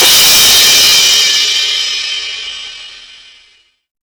CRASHDIST1-R.wav